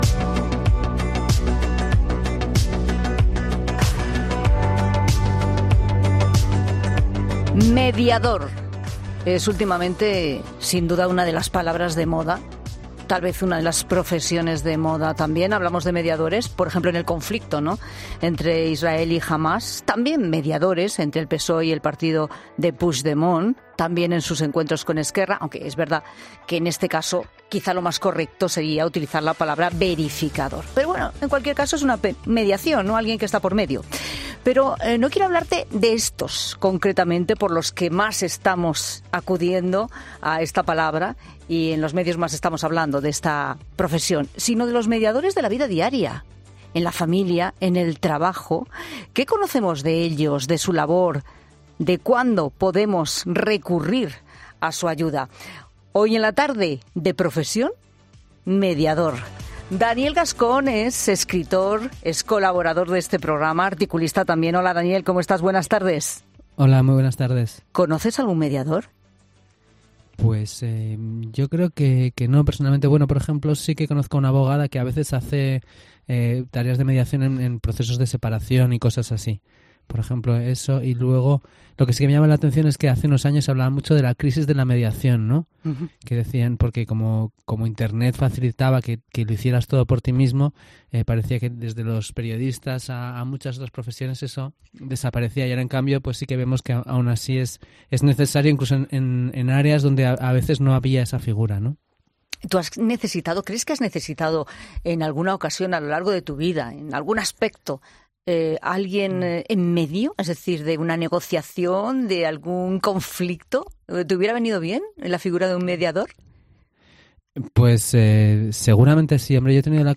De Israel a Junts y el PSOE: Dos mediadores profesionales desvelan en COPE cuáles son sus técnicas para acercar posturas